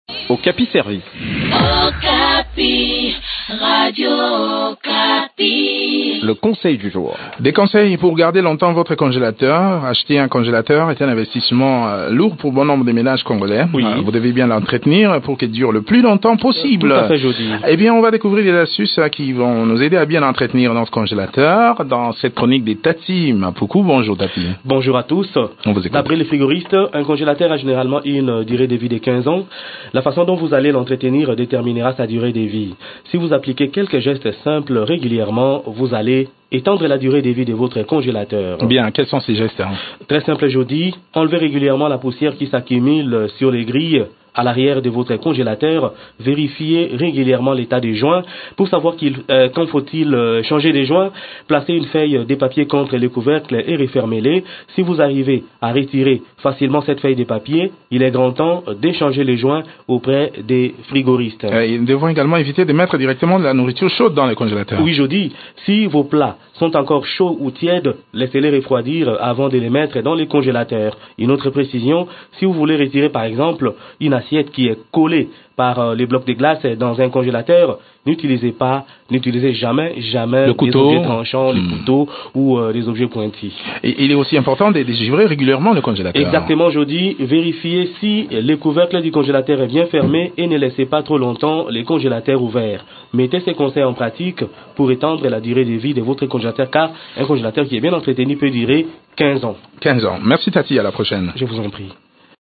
Découvrez des astuces qui peuvent vous aider à bien entretenir votre congélateur dans cette chronique